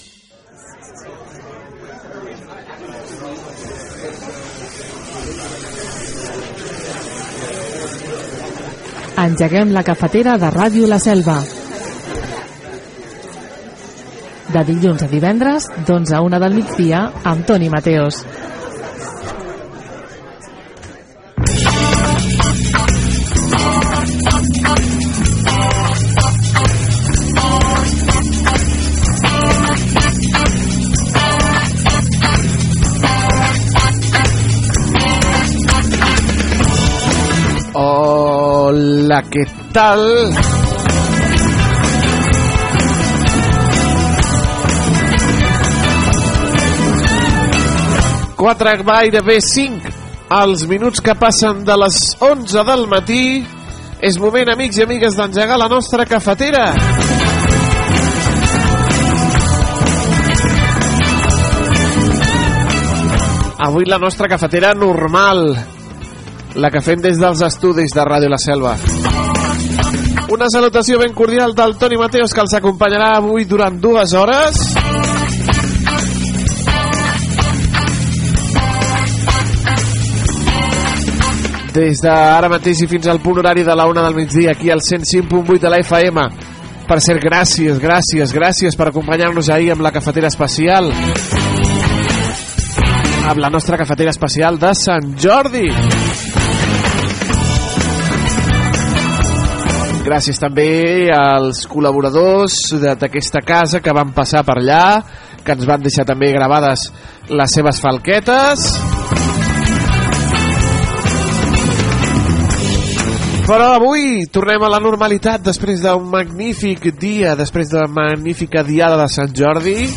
Careta del programa, salutació inicial, sumari de continguts i informació de com va anar la diada de Sant Jordi a la Selva del Camp.
Entreteniment